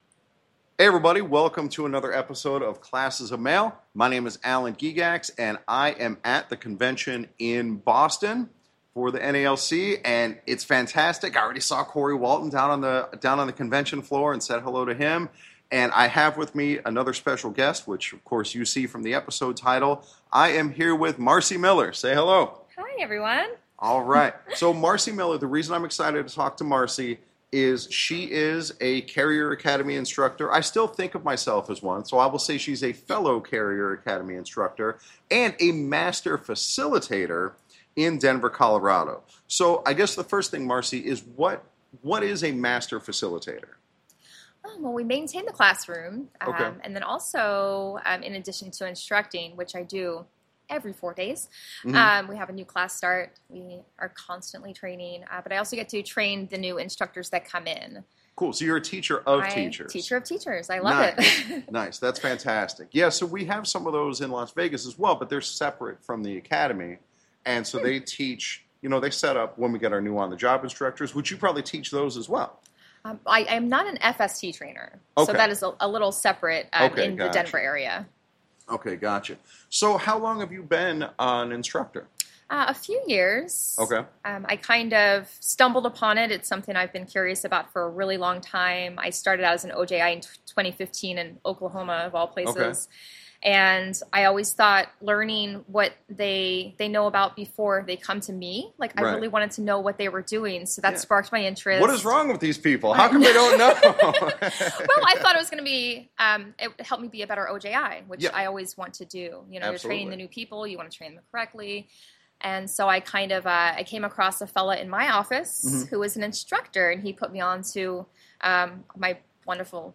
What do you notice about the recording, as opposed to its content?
The convention is awesome!